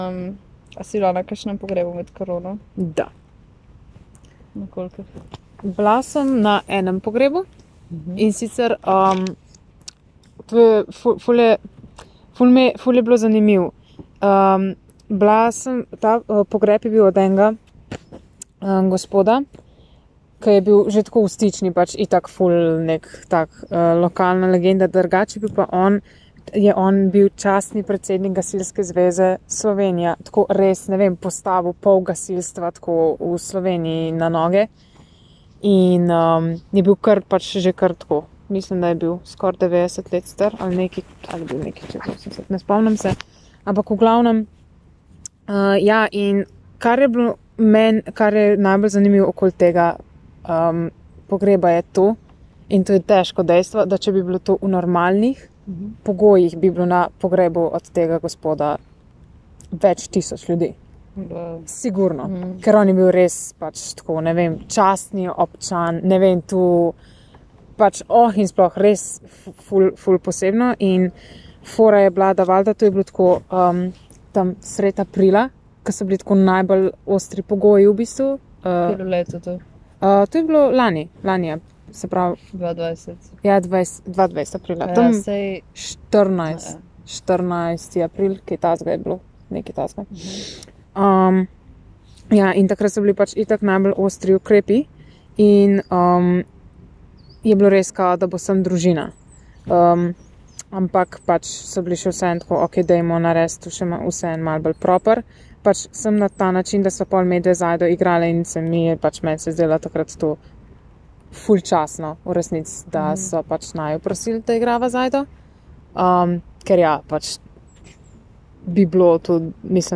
Intervju z glasbenico o pogrebu v času koronavirusa
Lokacija: Stična